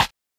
SnareRim Groovin 1.wav